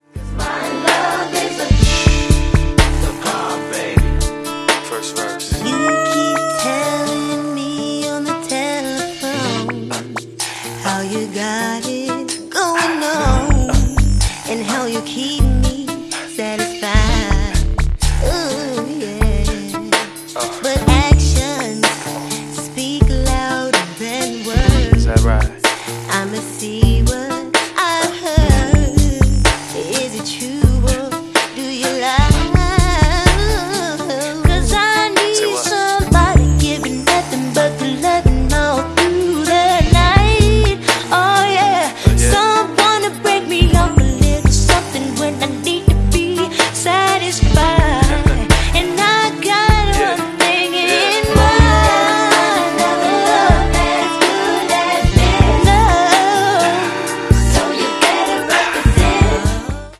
giving straight-up R&B seduction with just the right edge.
Smooth, slick vocals with just enough bump